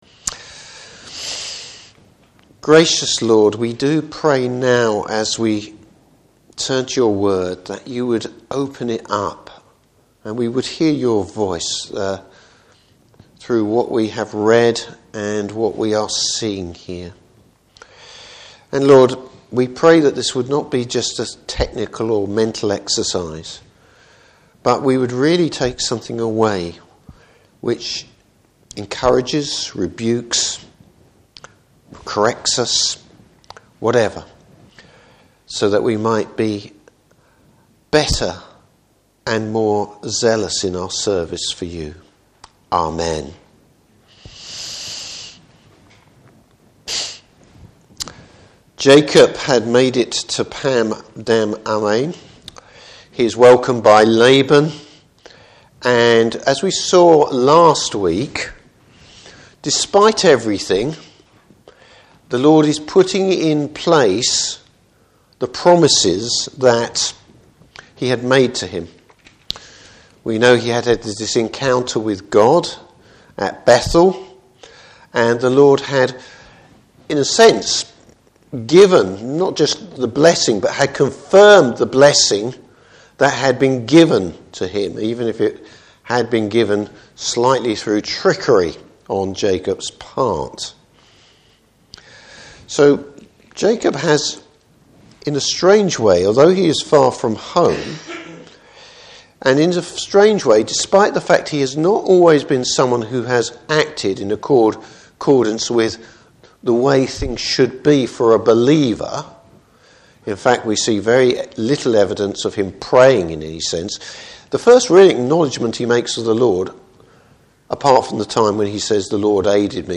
Service Type: Evening Service Jacob gests a taste of his own medicine!